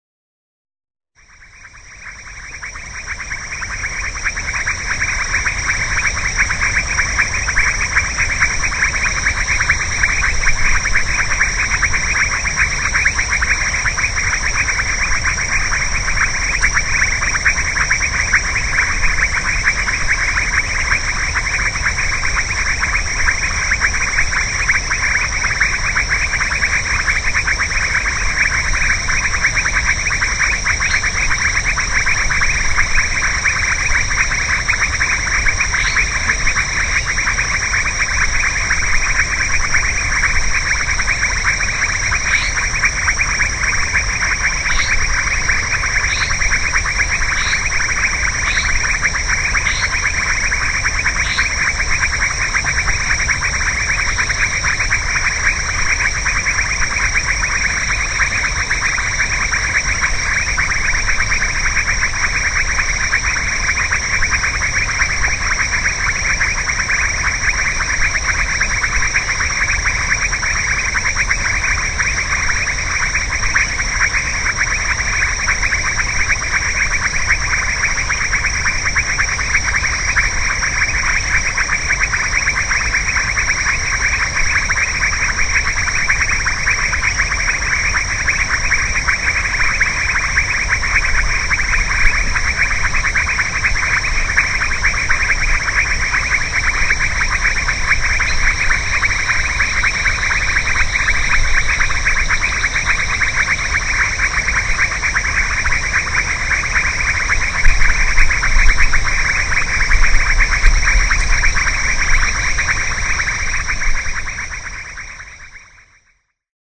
Frogs at Gran Sabana